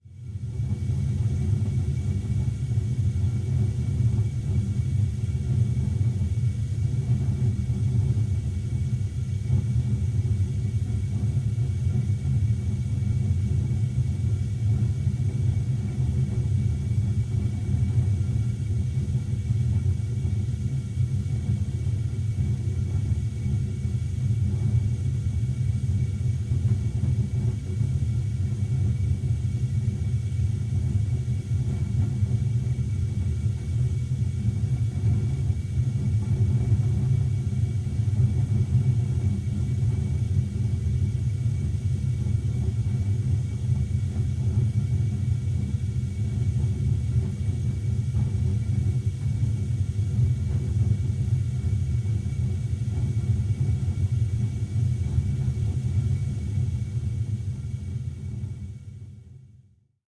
На этой странице собраны звуки работающей газовой колонки – от розжига до равномерного гудения.
Шум и звук газовой колонки